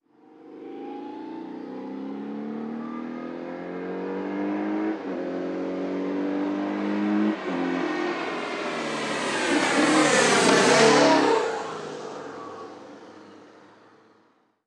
Moto marca BMW pasando a velocidad normal 2
motocicleta
Sonidos: Transportes